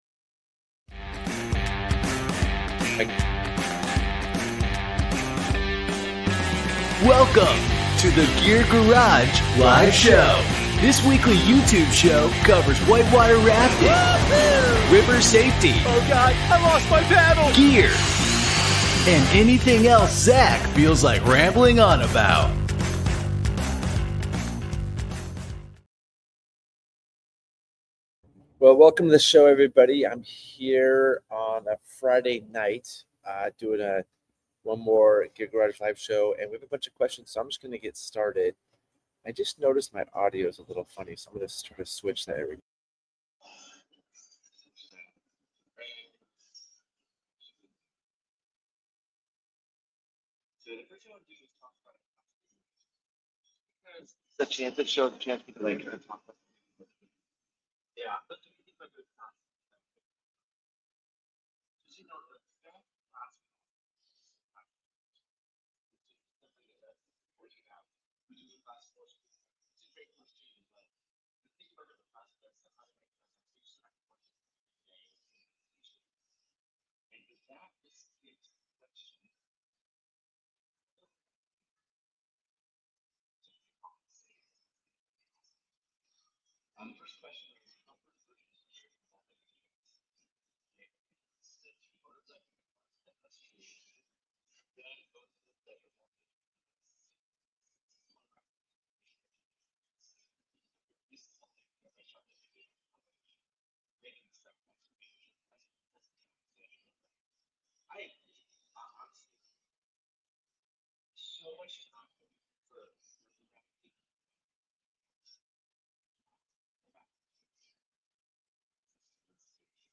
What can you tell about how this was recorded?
This podcast is the audio version of the Gear Garage Live Show, where we answer submitted questions and talk all things whitewater.